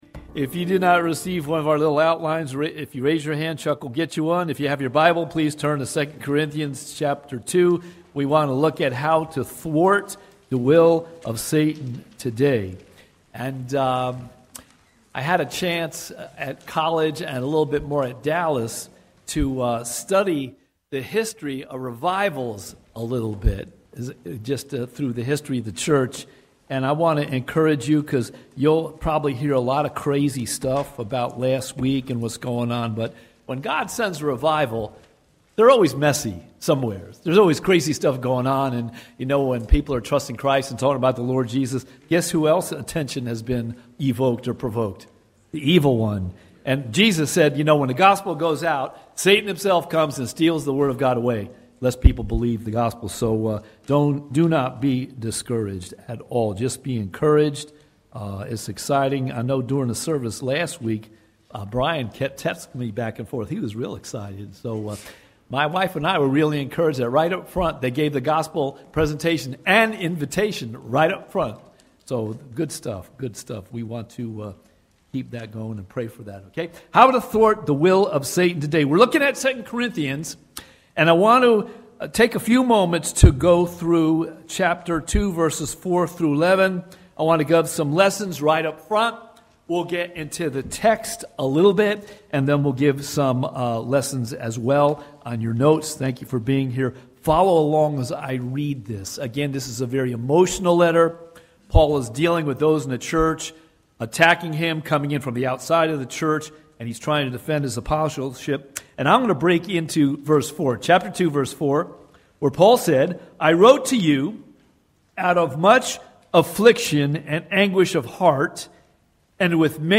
Watch Online Service recorded at 9:45 Sunday morning.